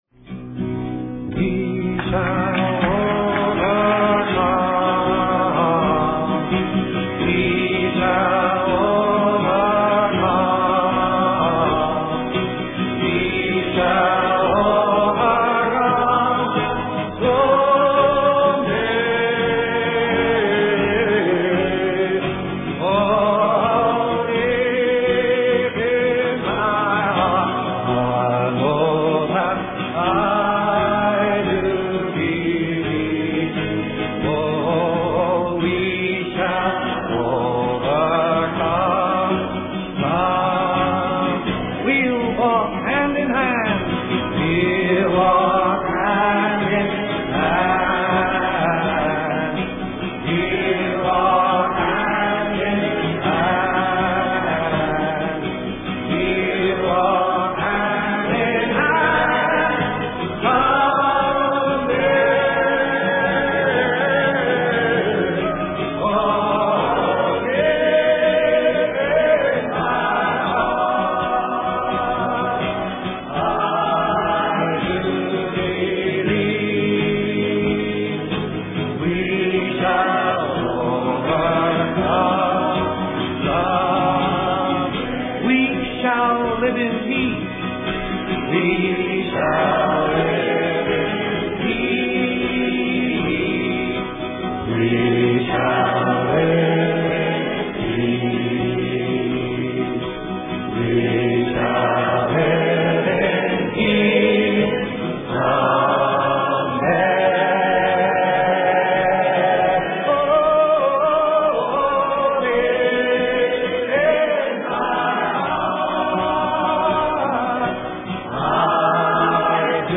Собственно говоря, эту особенность песни Пит Сигер ещё больше подчеркивал, перемежая своё исполнение другими, всякий раз актуальными, лозунгами и обращениями, которые он, словно на митинге, выкрикивал в зал, призывая слушателей петь вместе с ним и достигая с публикой полного контакта и взаимопонимания.
Послушайте, например, его широко известное исполнение «We shall overcome» на концерте в Карнеги-Холл 8 июня 1963 года: